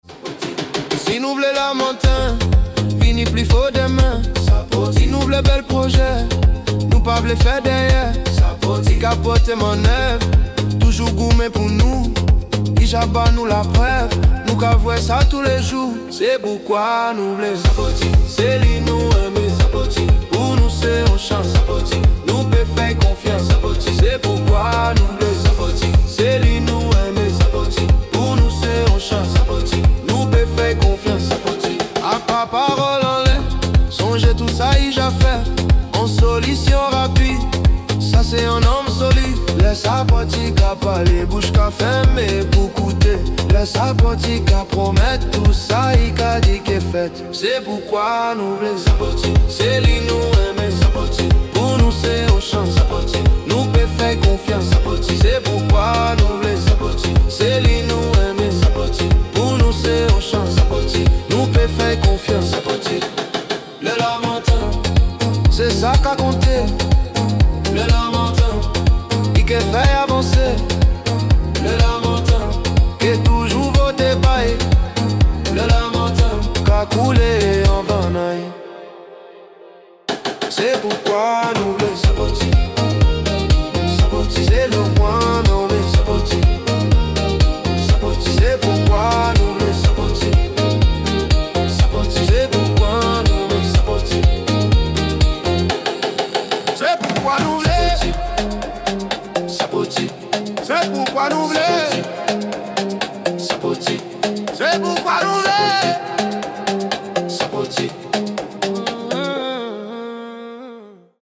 CHANSON OFFICIELLE